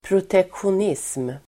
Ladda ner uttalet
Uttal: [proteksjon'is:m]
protektionism.mp3